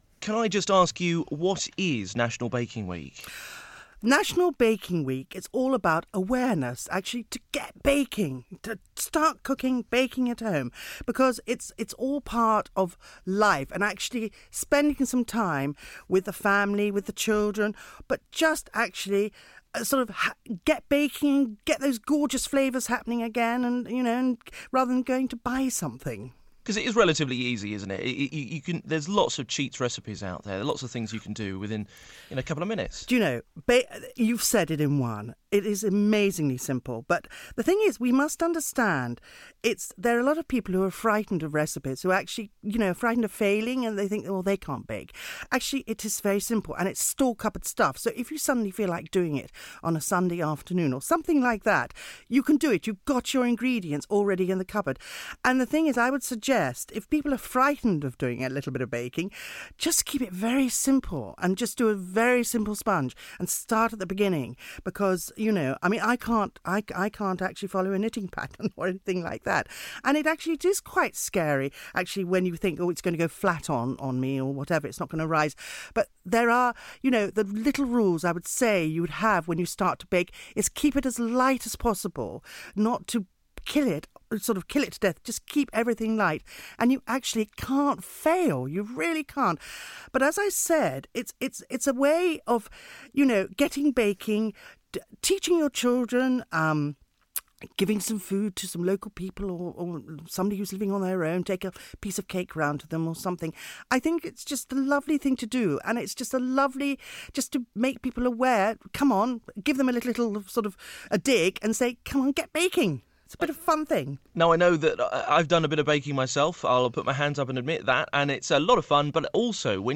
Rosemary Shrager raises awareness of baking at home on Sky News.